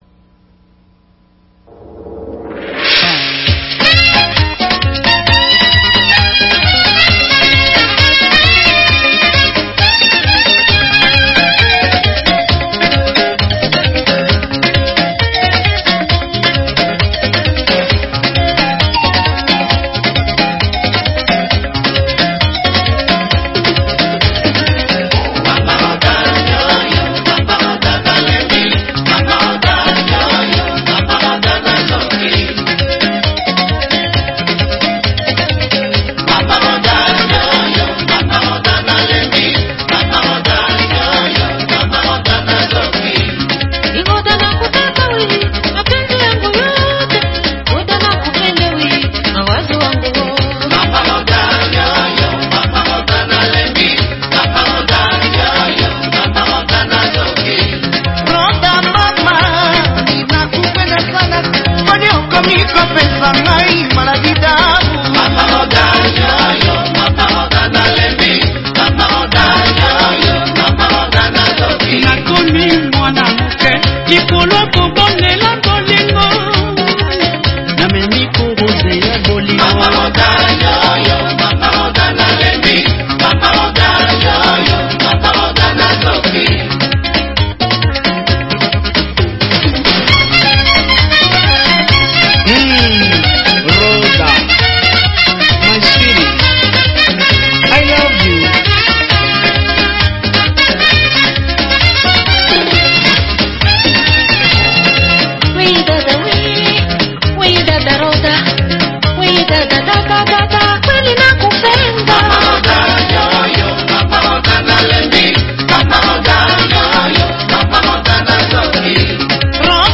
Rhumba